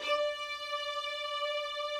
strings_062.wav